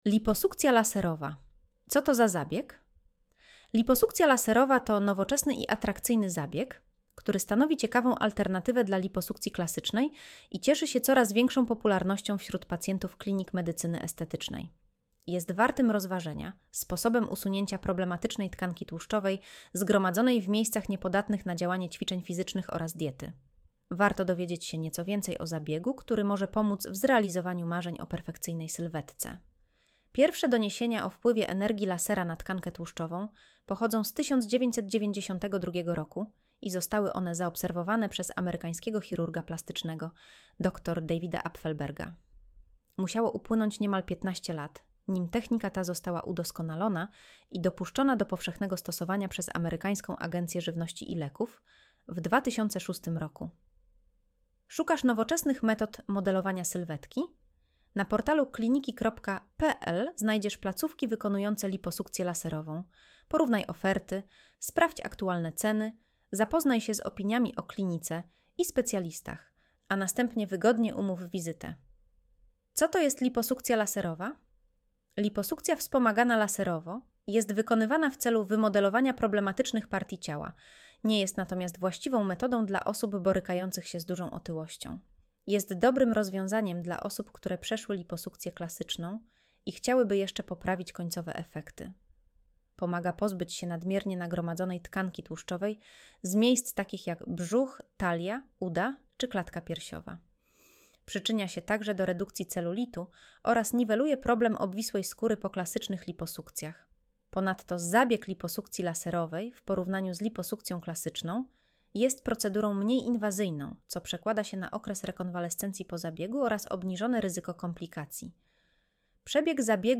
Streść artykuł Słuchaj artykułu Audio wygenerowane przez AI, może zawierać błędy 00:00